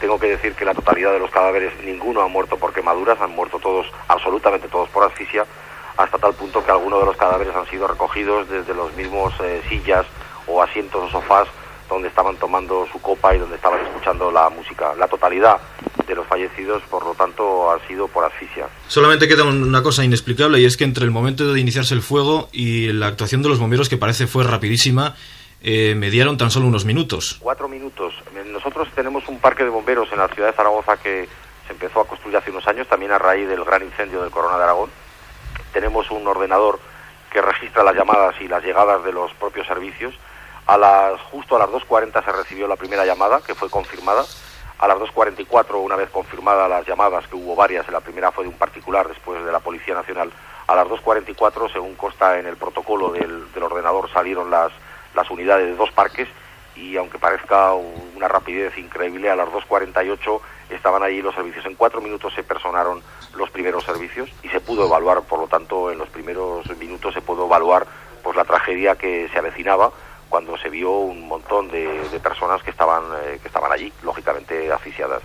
Entrevista telefònica a l'alcalde en funcions de Saragossa Luis García Nieto.
Informatiu